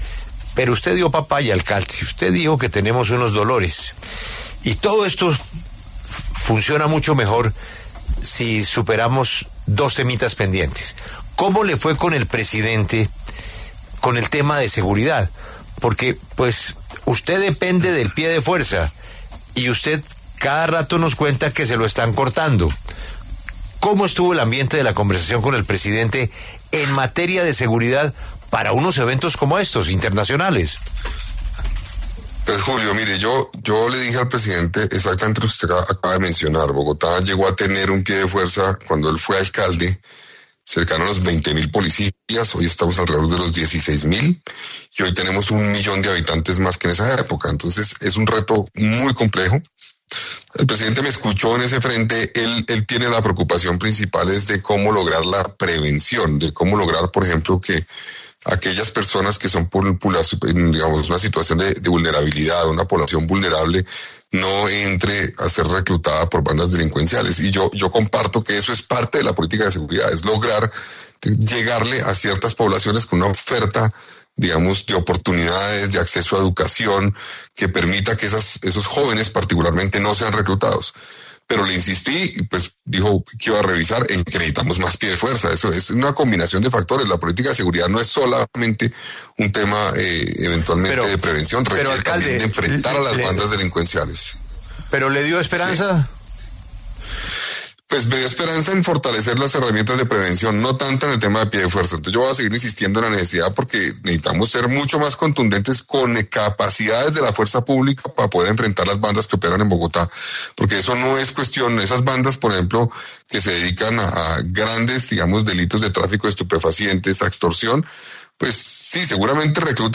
Ante este último tema, que aqueja a los ciudadanos, el alcalde Carlos Fernando Galán aseguró en La W, con Julio Sánchez Cristo, que puso sobre la mesa la necesidad de fortalecer el pie de fuerza en Bogotá.